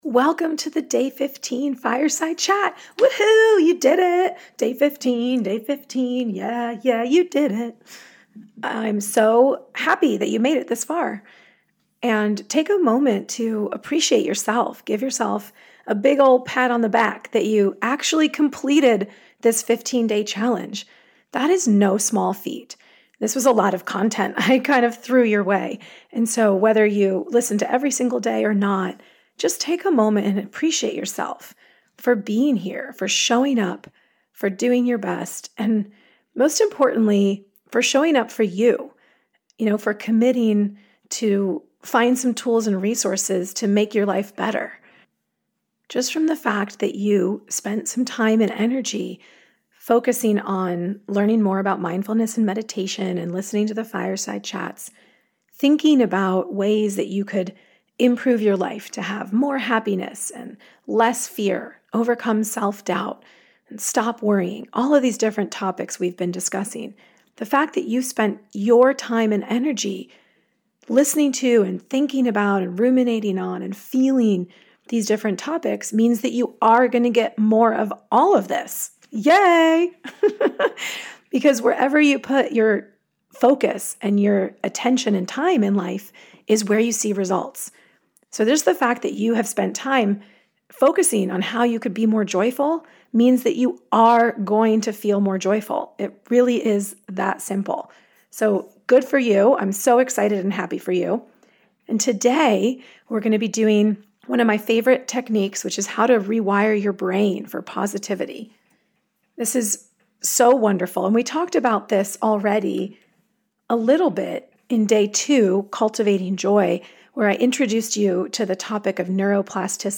Day 15 - Rewire your Brain for Positivity [FIRESIDE CHAT]